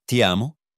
1. "Ti" = "tee": Short and crisp.
2. "Amo" = "AH-moh": Stress on the first syllable.